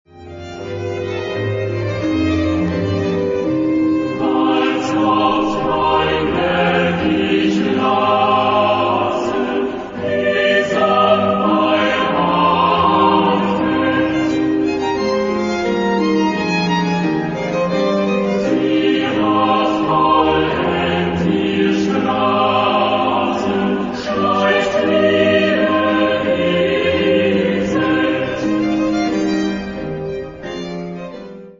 Type de choeur : SATB  (4 voix mixtes )
Instruments : Violon (2) ; Basse continue
Tonalité : do mineur